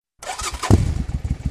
starter_moto.mp3